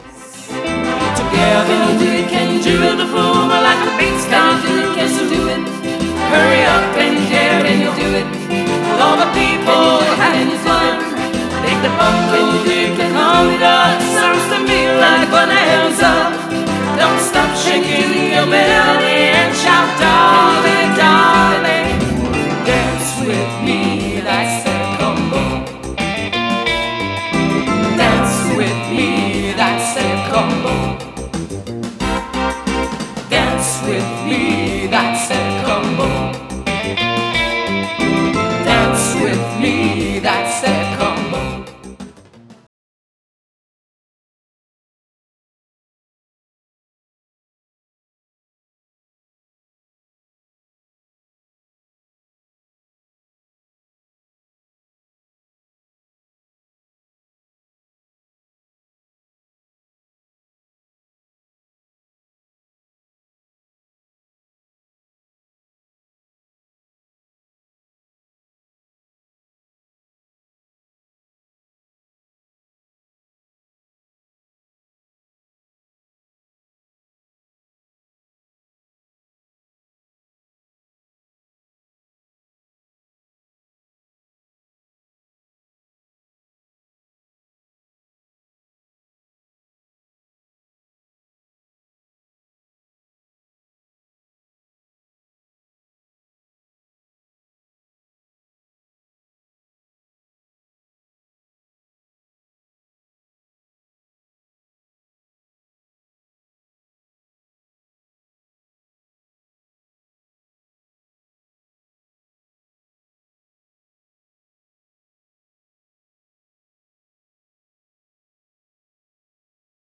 Enregistrement, Studio
Guitares: Électrique / Acoustique
Piano / Orgue
Choriste